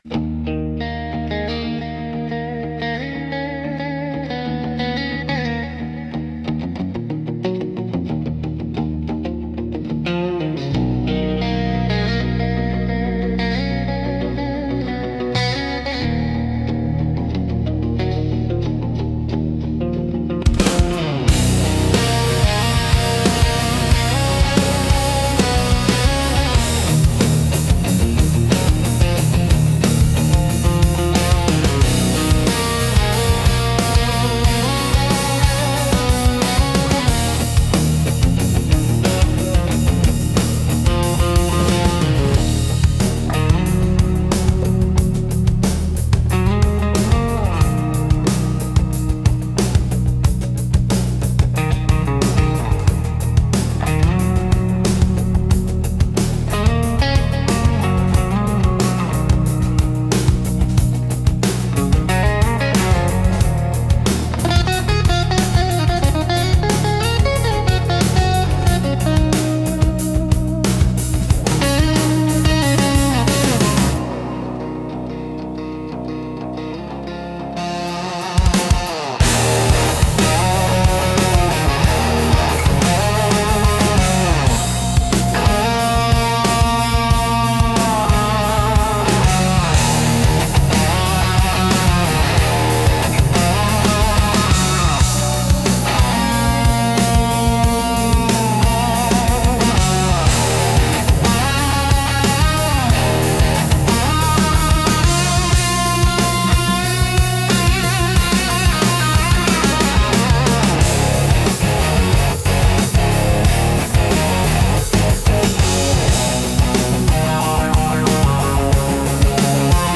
Instrumental - Solace in Open Tuning 4.07